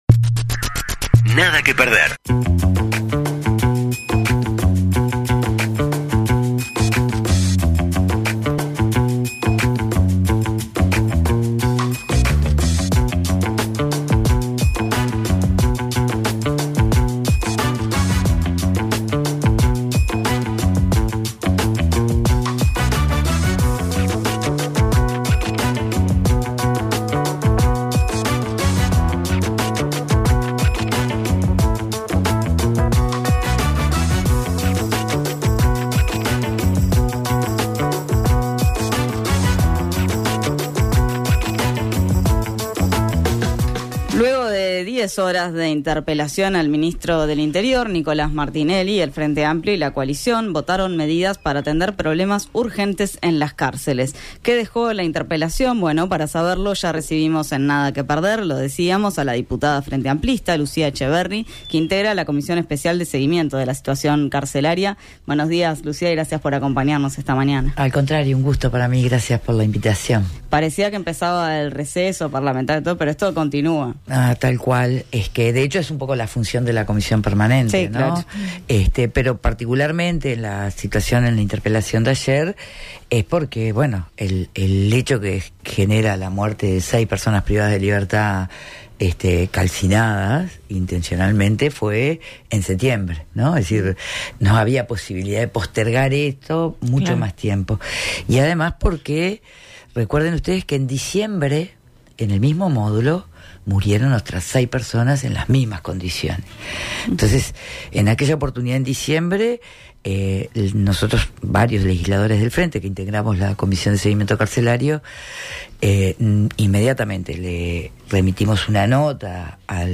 La entrevista en Nada que perder con la diputada frenteamplista Lucía Etcheverry, que integra la comisión especial de seguimiento de la situación carcelaria